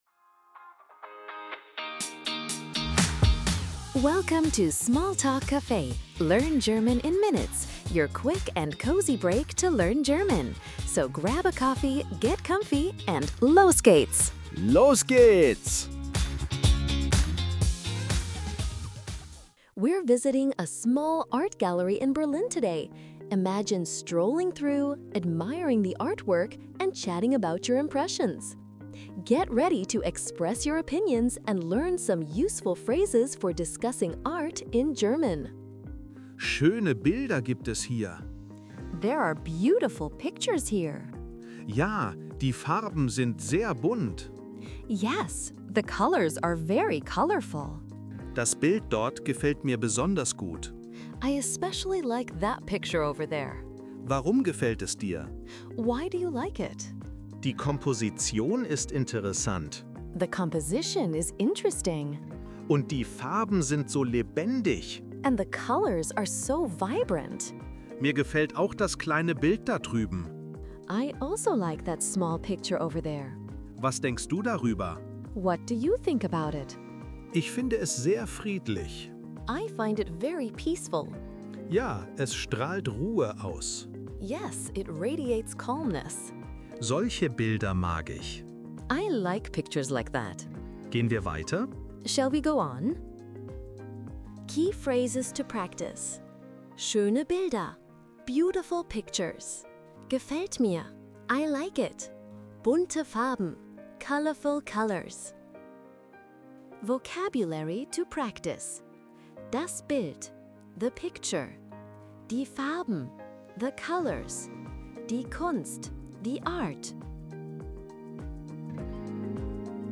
Real-life German conversations. Immerse yourself!